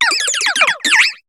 Cri de Candine dans Pokémon HOME.